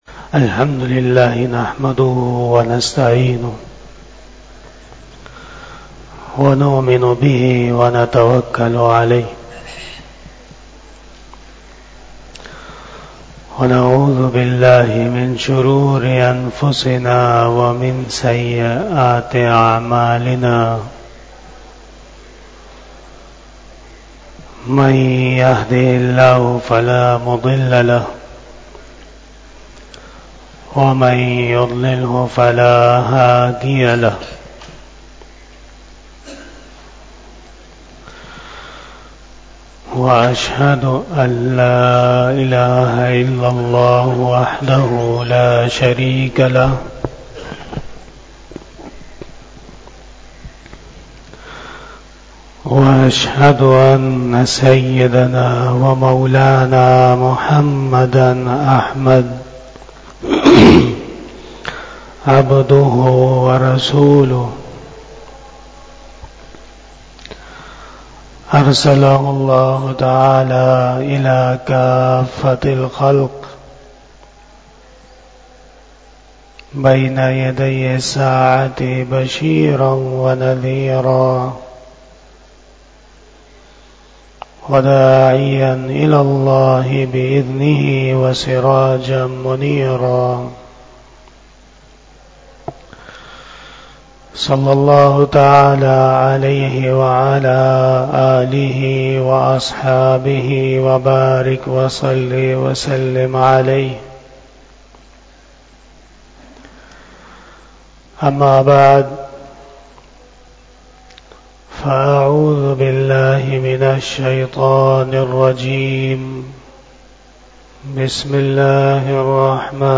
Khitab-e-Jummah